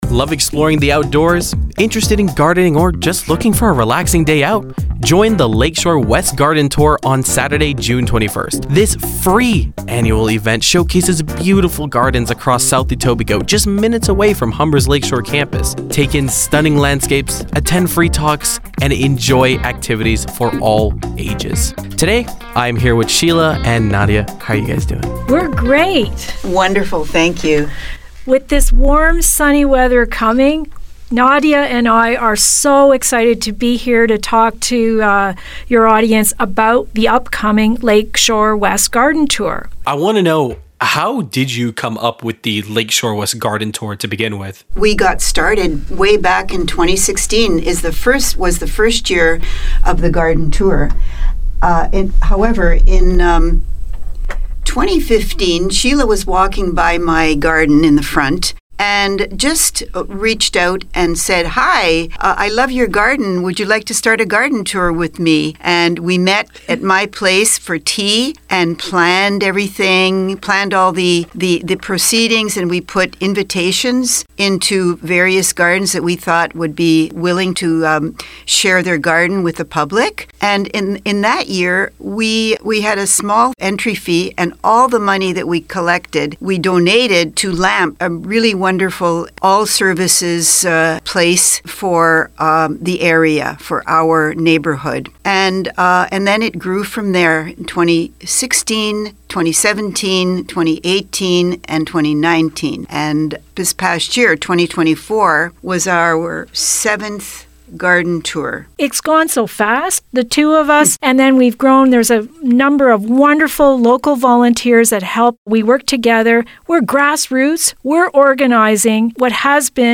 HumberInterview.mp3